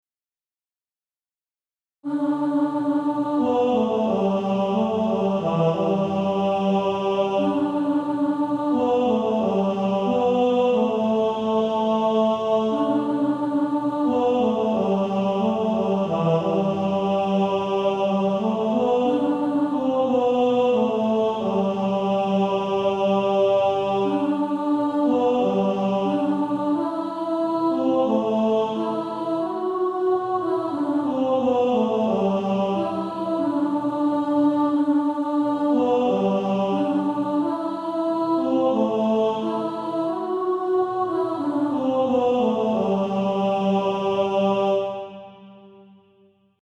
Author: Author unknown – Traditional melody
Practice then with the Chord quietly in the background.